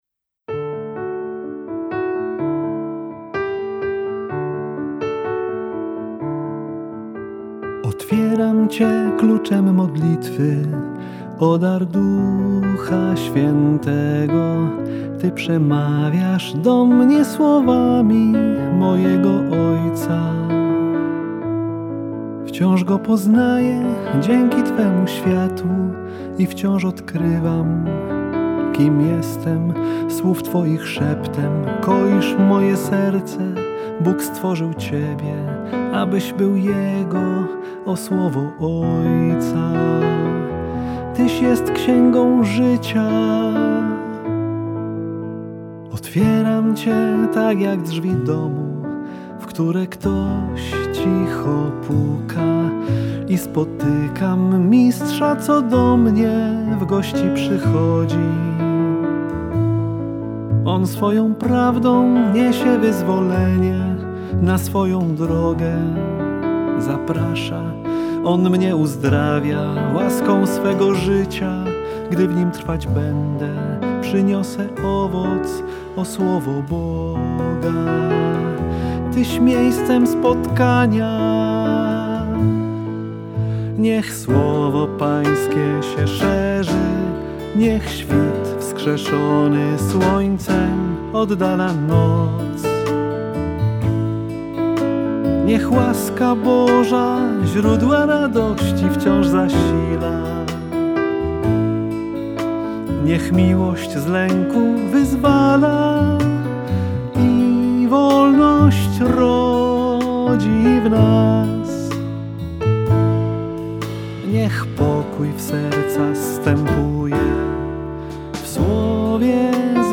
AUDIO MUSICAL – Versión polaca.